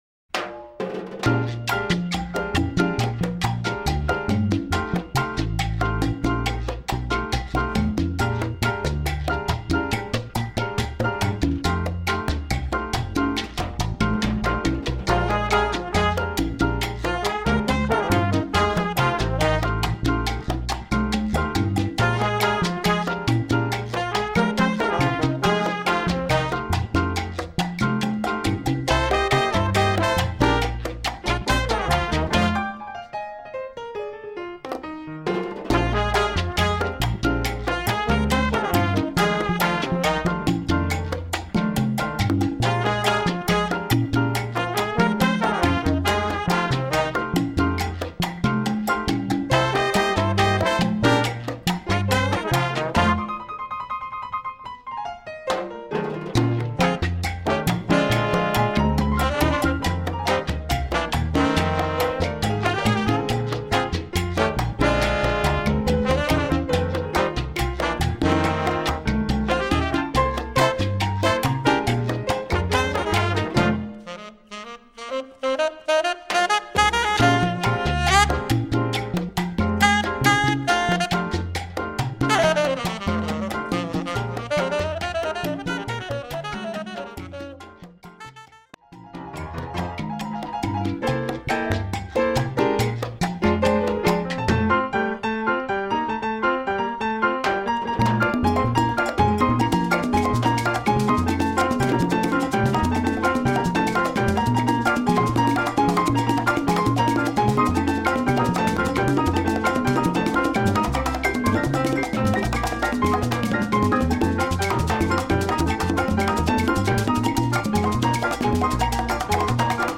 Latin jazz
Category: combo (septet)
Style: cha cha